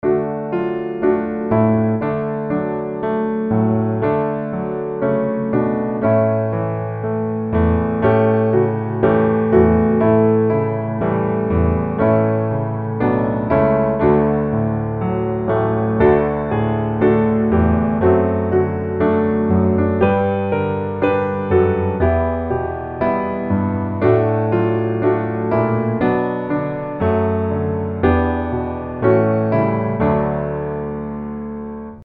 D Major